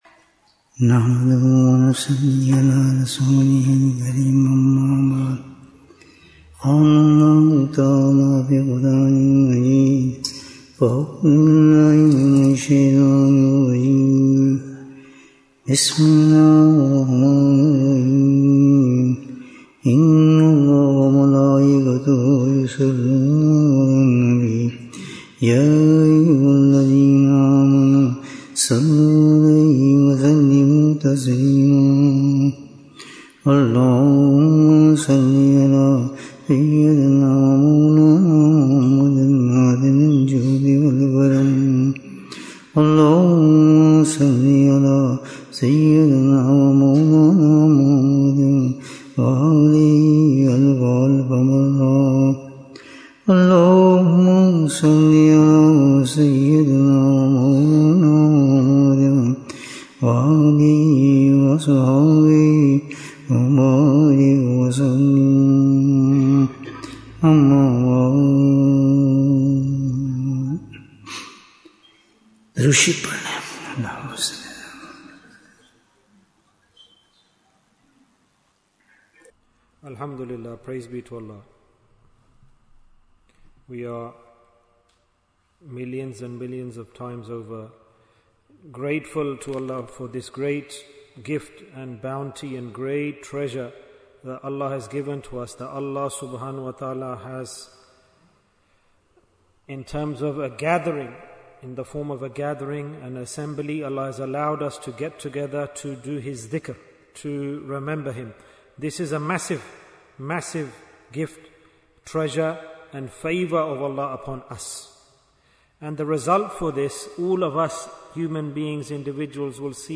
Bayan, 43 minutes31st July, 2025